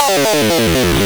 gameover1.wav